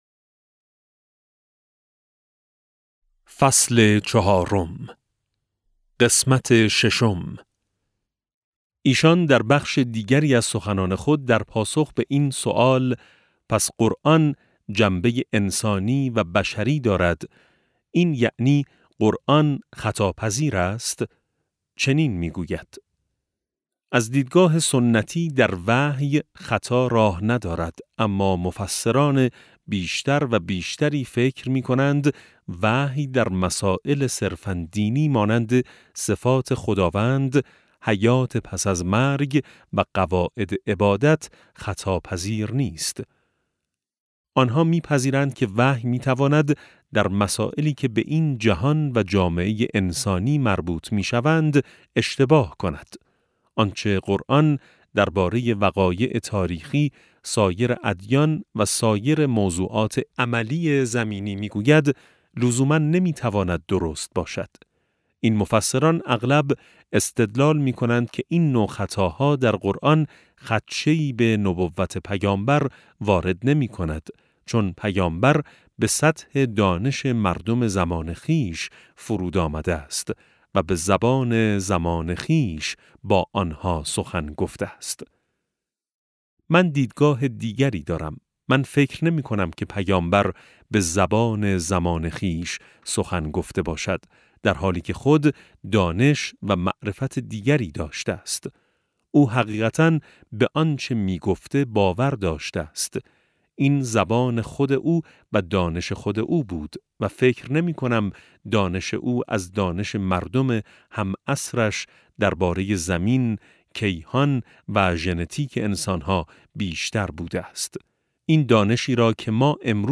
افق وحی - فصل چهارم (370 ـ 390) - کتاب صوتی - کتاب صوتی افق وحی - بخش21 - آیت‌ الله سید محمد محسن طهرانی | مکتب وحی